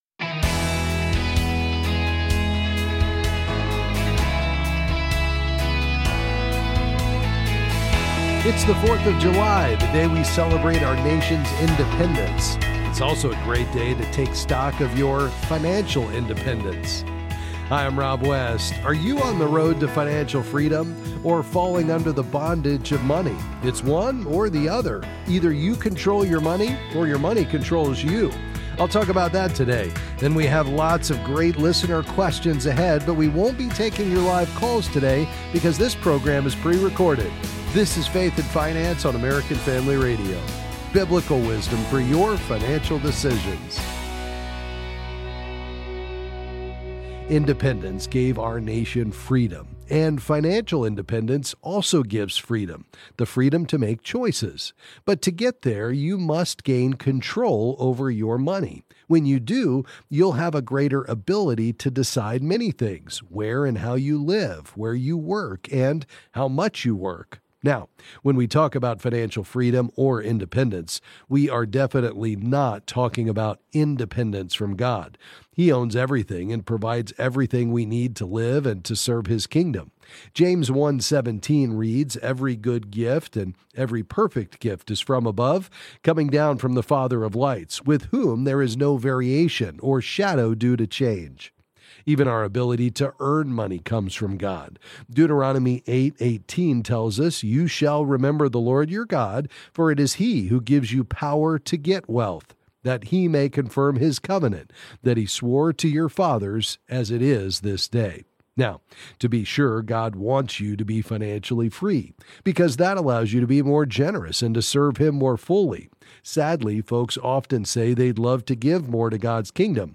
Then he answers questions on various financial topics.